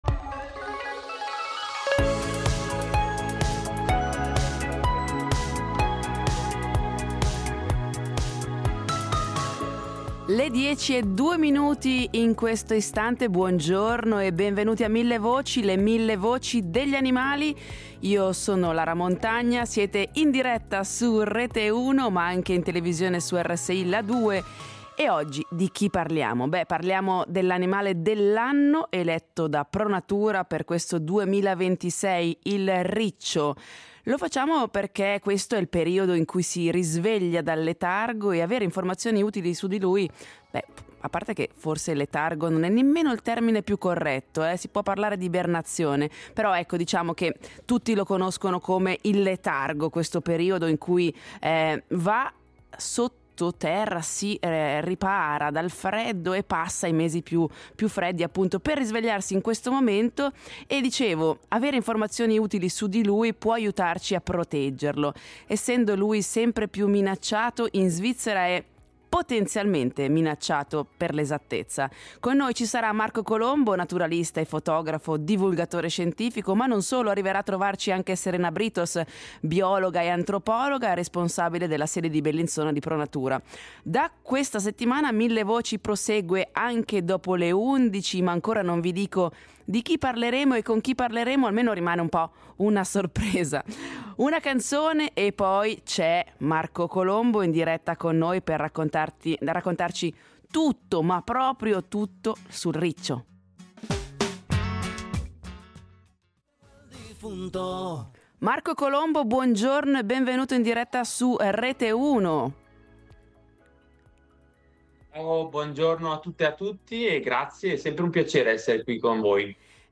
Tra questi, il riccio, affamato dopo mesi di sonno. Ne parliamo con due biologi in studio, in diretta a “ Millevoci ”.